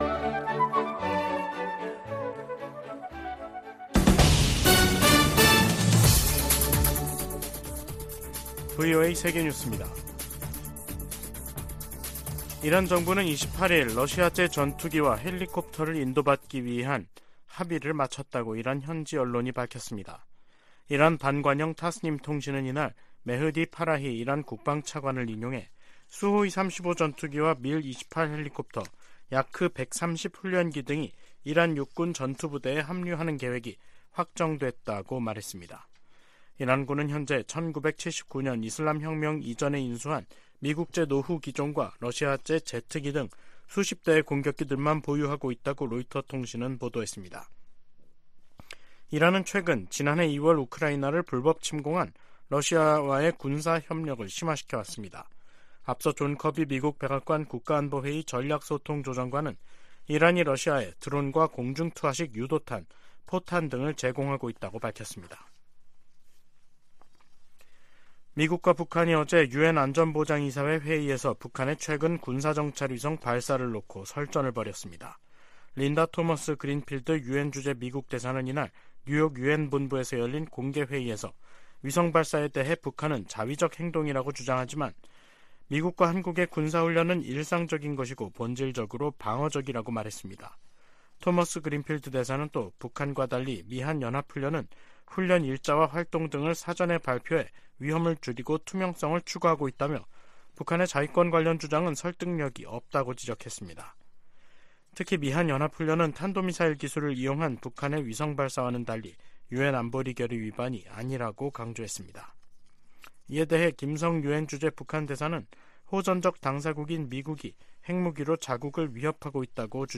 VOA 한국어 간판 뉴스 프로그램 '뉴스 투데이', 2023년 11월 28일 3부 방송입니다. 북한은 군사정찰위성 ‘만리경 1호’가 백악관과 군 기지 등 미국 본토 내 주요 시설을 촬영했다고 주장했습니다.